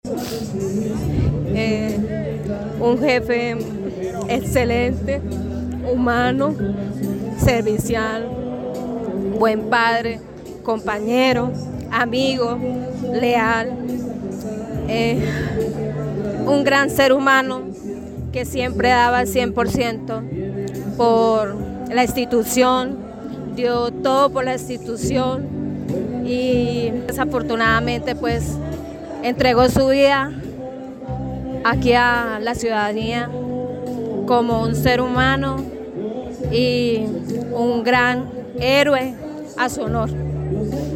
Al homenaje también asistieron sus colegas.